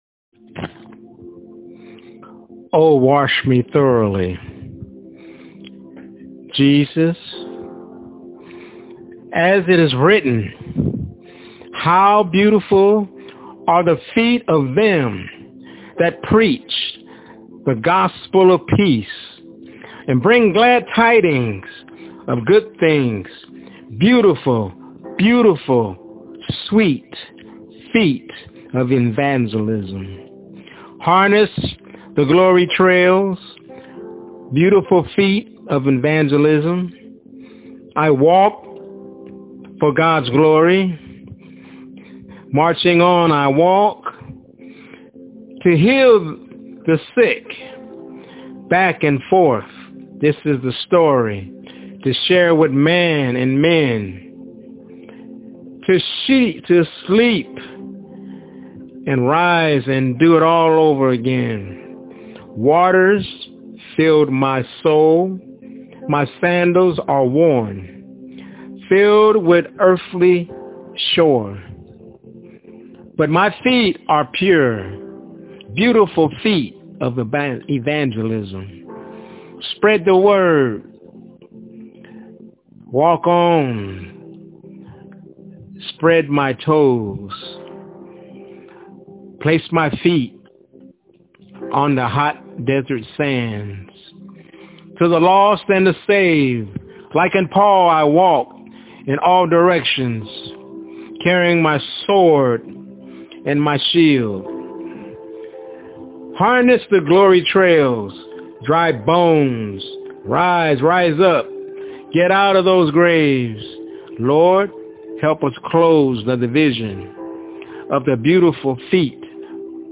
Beautiful Feet of Evangelism- My Spokenword